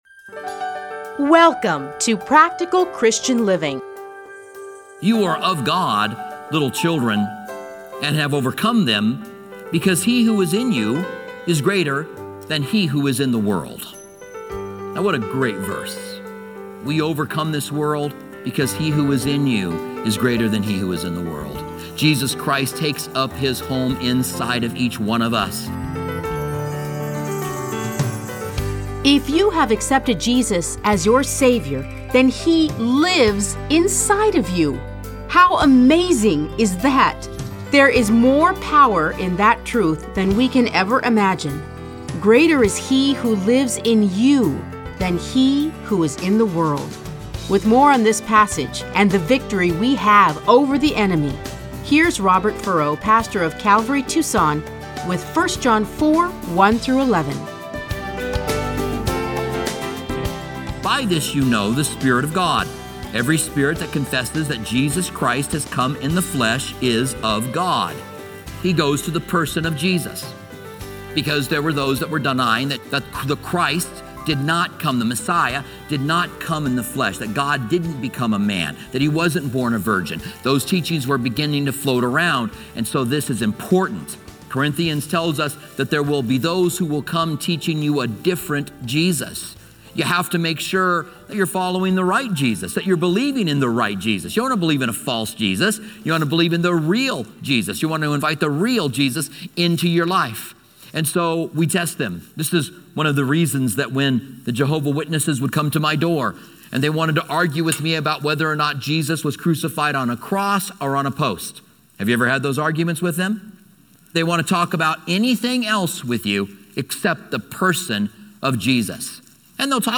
Listen to a teaching from 1 John 4:1-11.